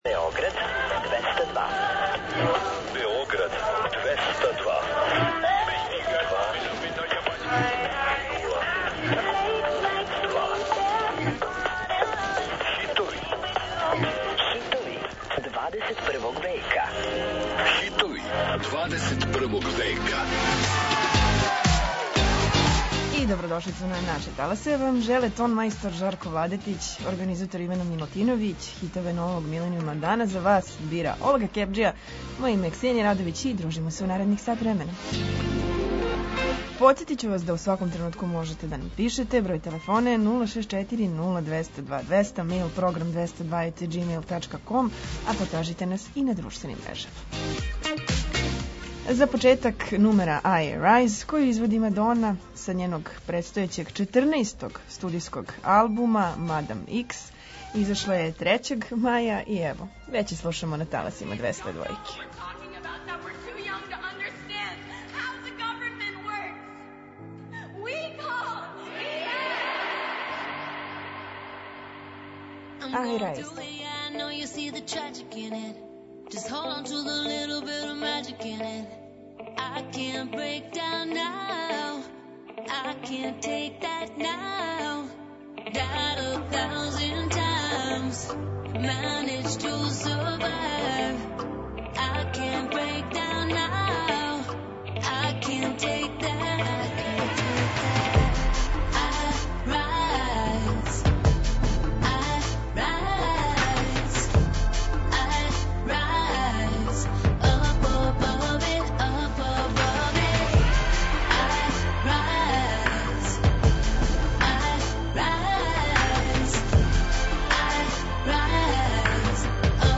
Чућете песме које заузимају сам врх светских топ листа.